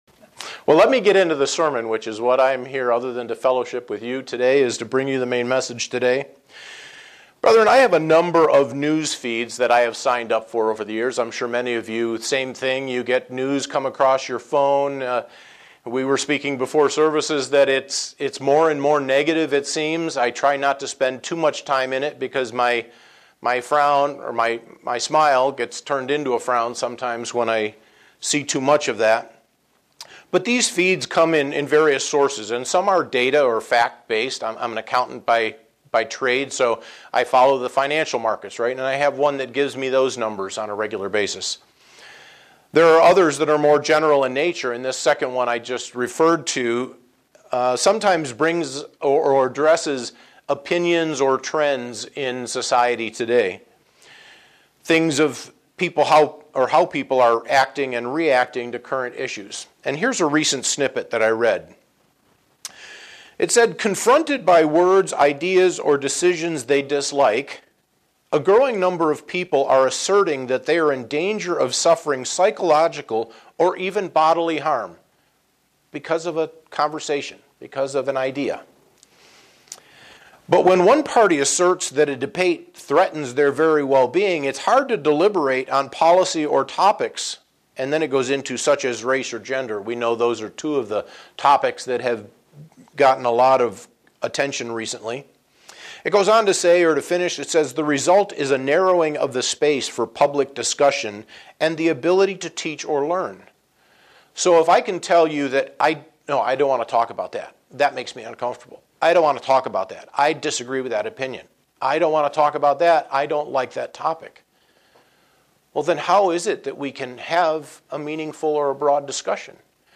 Sermons
Given in Buffalo, NY